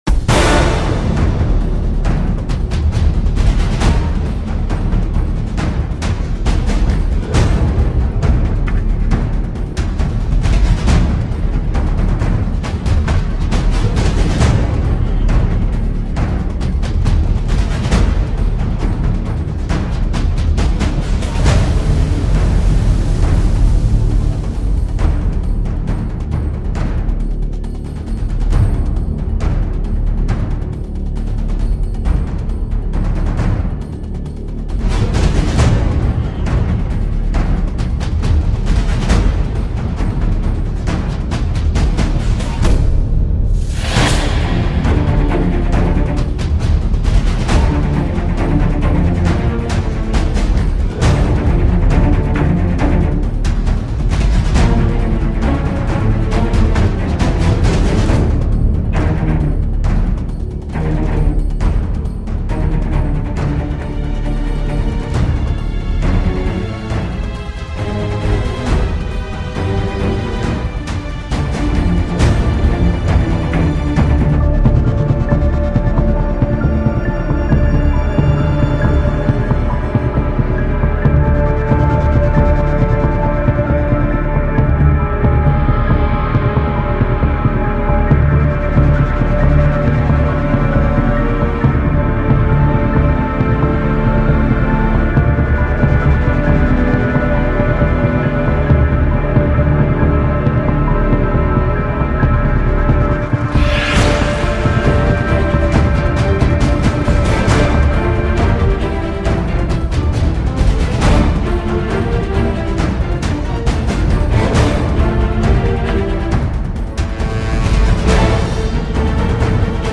Шум морского сражения